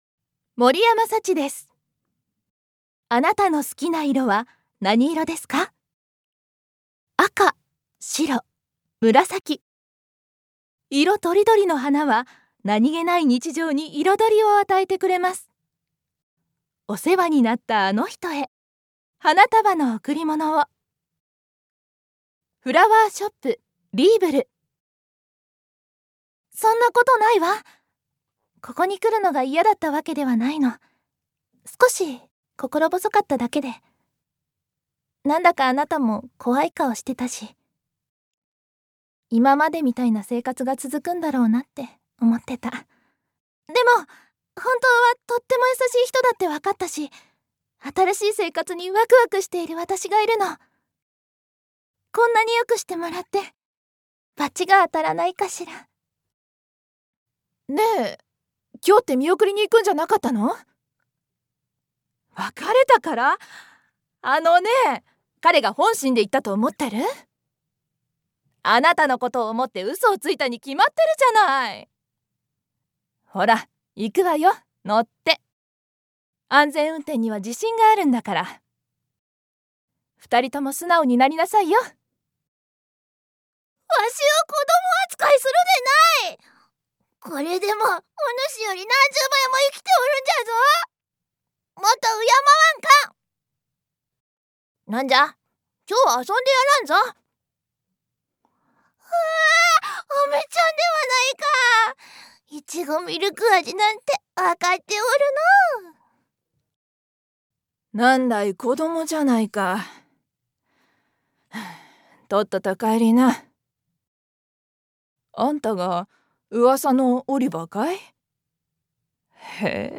One shot Voice（サンプルボイスの視聴）